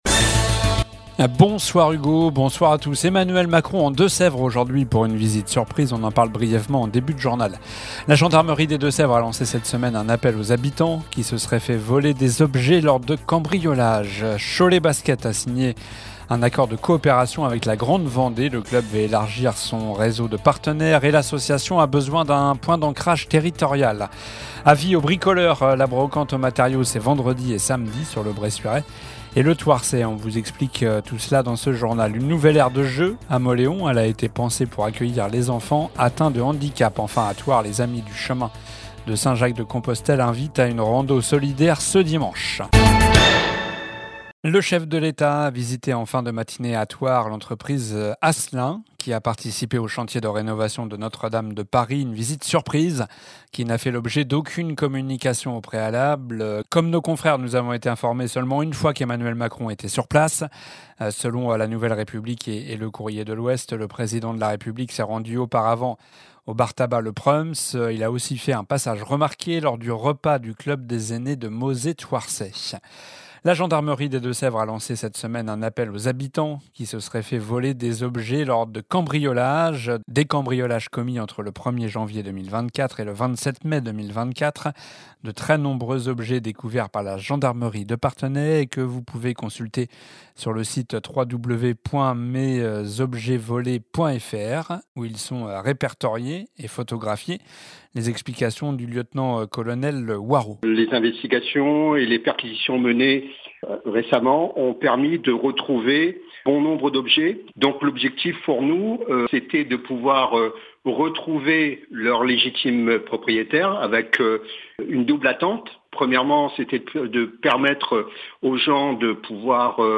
Journal du jeudi 10 avril (soir)